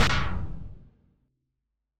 obstacle_death.mp3